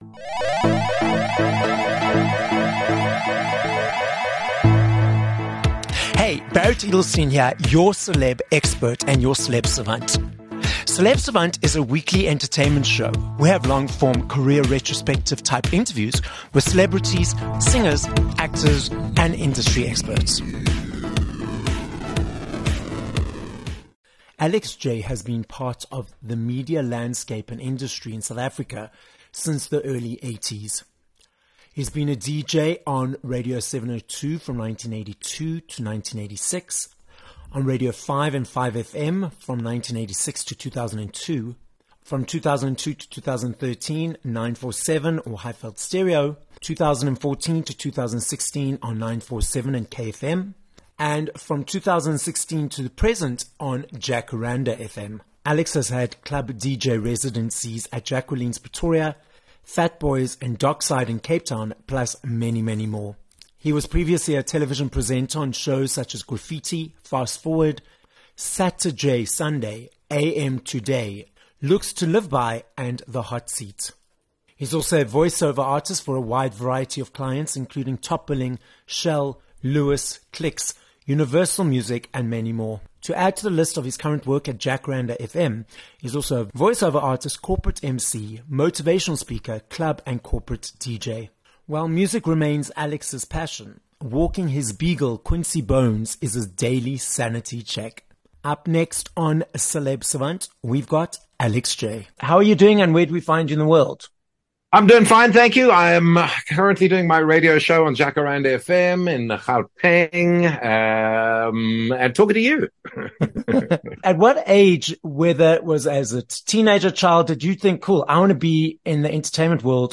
7 Jan Interview with Alex Jay
With over 40 years’ experience in the media industry - South African DJ, club DJ, MC, voice over artist and television presenter, Alex Jay, joins us on this episode of Celeb Savant. Alex tells us why he still loves DJing, the difference between being on live radio and being a presenter on television, and much more as we explore his multi-decade success story in the industry.